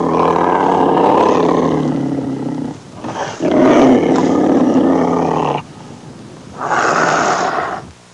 Angry Tigress Sound Effect
Download a high-quality angry tigress sound effect.
angry-tigress.mp3